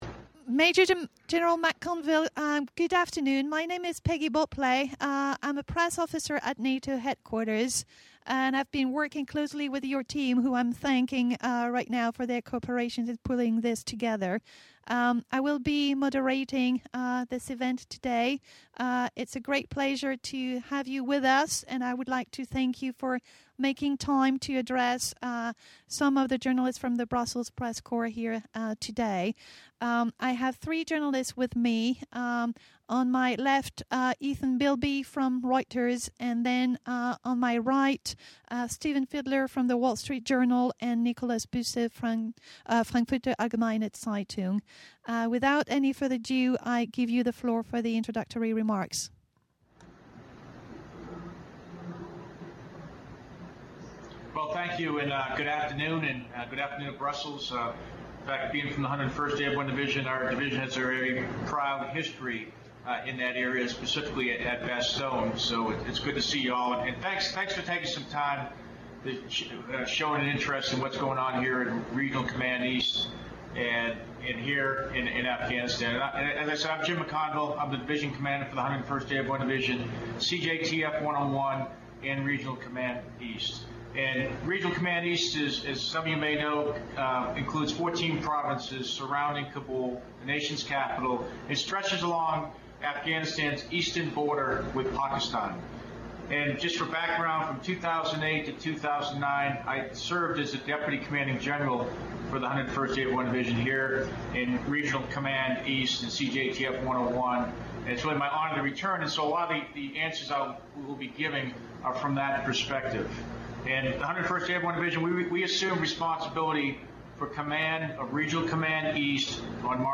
Video-news conference with Maj. Gen. James C. McConville , ISAF, Commander Regional Command East